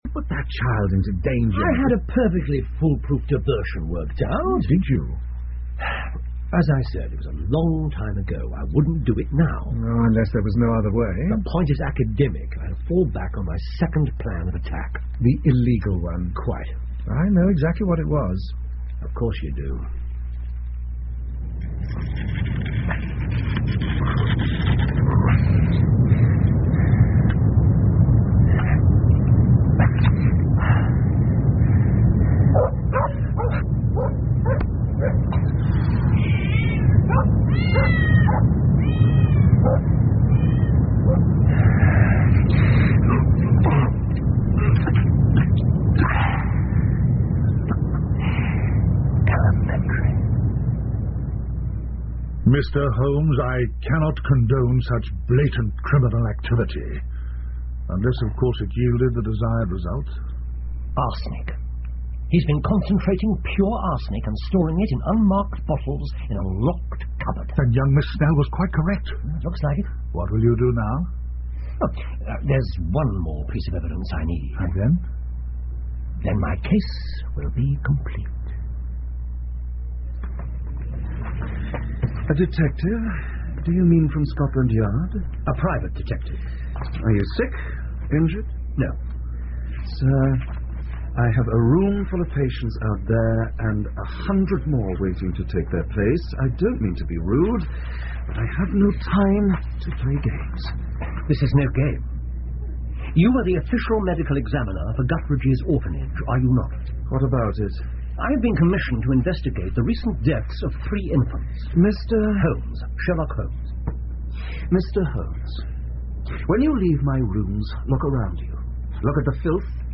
福尔摩斯广播剧 The Saviour Of Cripplegate Square 6 听力文件下载—在线英语听力室
在线英语听力室福尔摩斯广播剧 The Saviour Of Cripplegate Square 6的听力文件下载,英语有声读物,英文广播剧-在线英语听力室